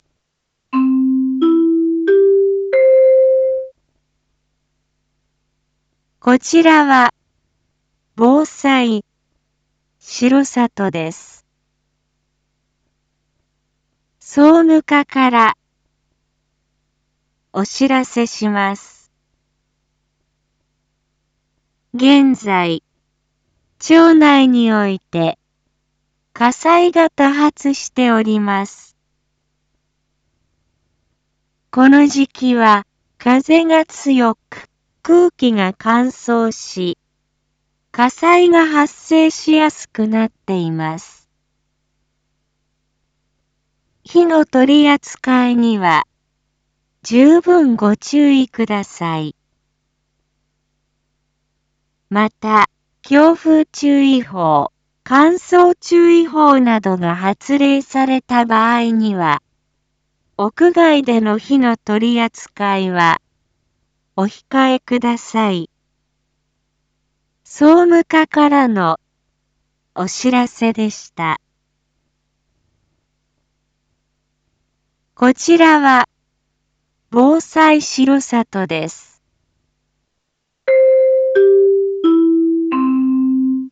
一般放送情報
Back Home 一般放送情報 音声放送 再生 一般放送情報 登録日時：2025-03-07 19:01:25 タイトル：火災注意喚起② インフォメーション：こちらは防災しろさとです。